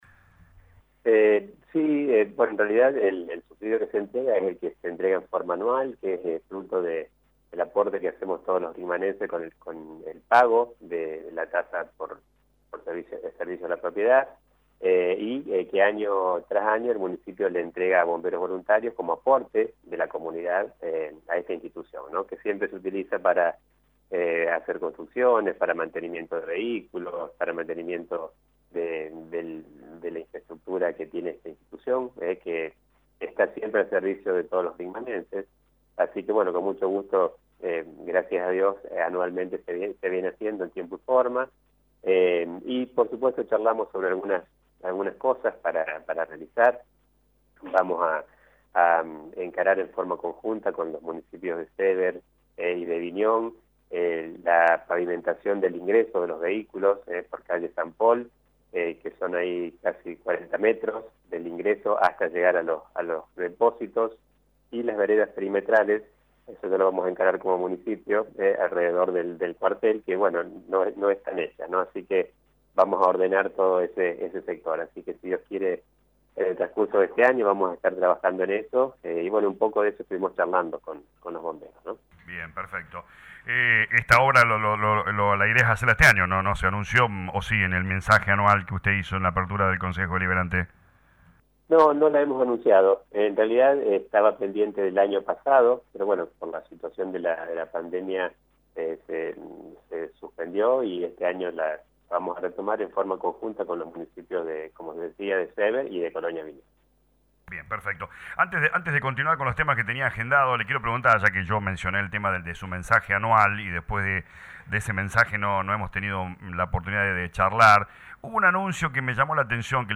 El intendente Dr. Gustavo Tevez habló con LA RADIO 102.9 sobre el proyecto de viviendas anunciado en su discurso anual.